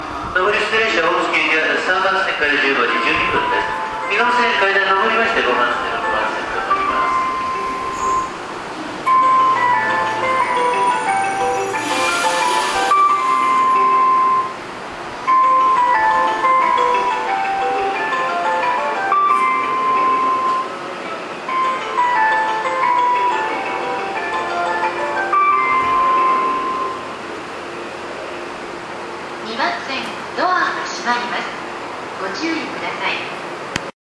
発車メロディー４コーラスです。甲府駅ではこちらのホームが一番鳴りやすいです。